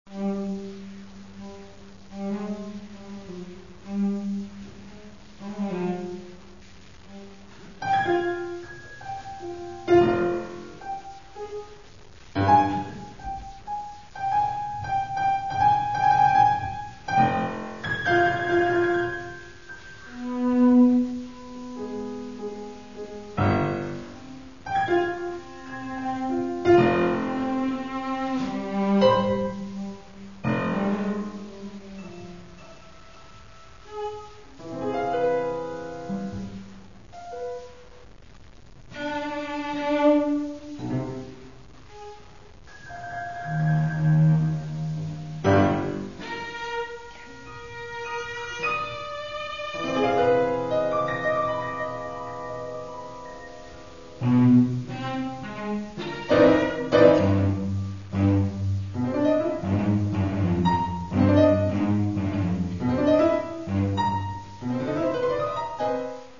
Каталог -> Класична -> Нео, модерн, авангард
Mp3Соната для віолончелі та фортепіано